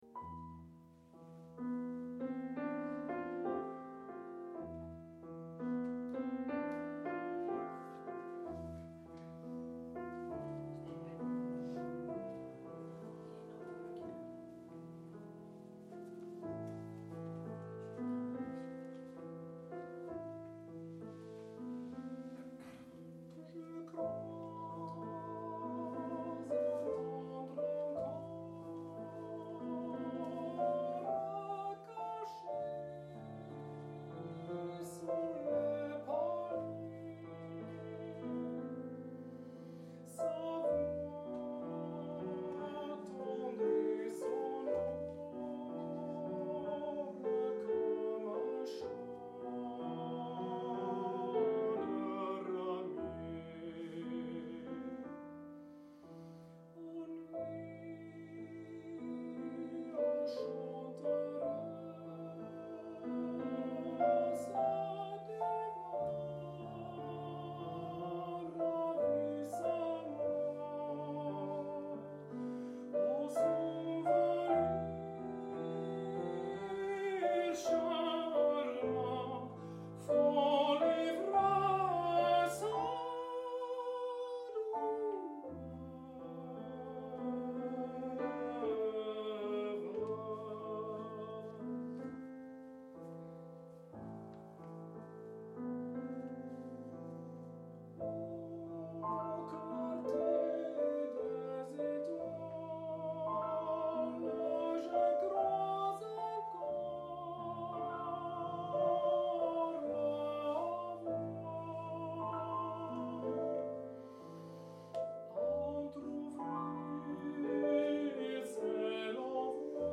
piano
tenor